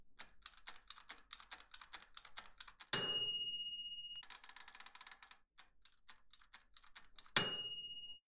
Timer
bell ding timer sound effect free sound royalty free Sound Effects